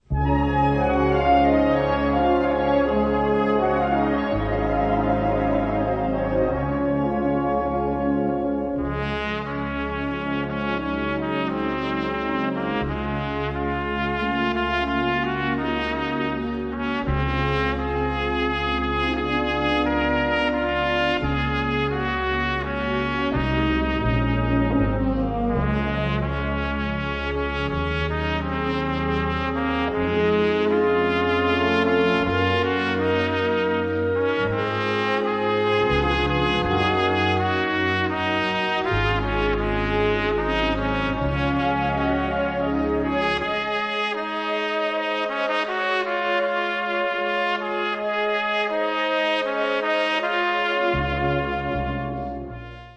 Gattung: Solostück für Trompete
Besetzung: Blasorchester